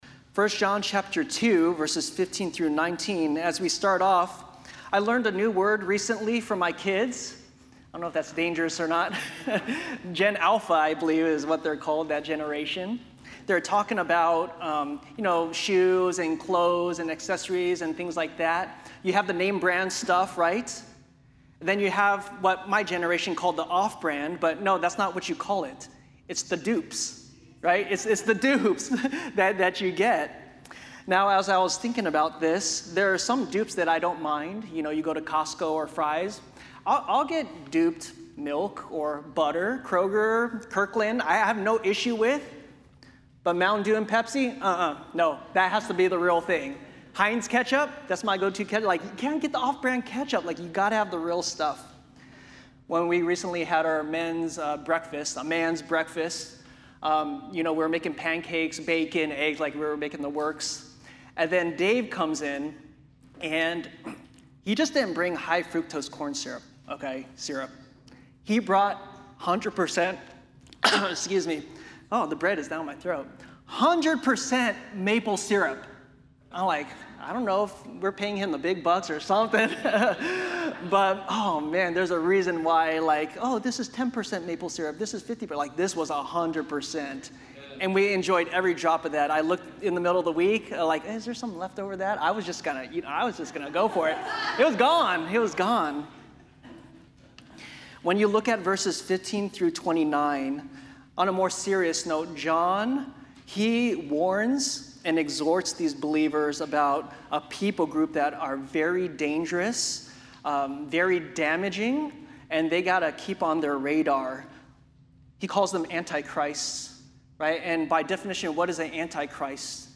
SERMONS | Desert Foothills Baptist Church